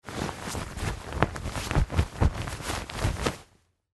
Звуки кровати
Шуршание одеяла